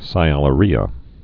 (sī-ălə-rēə)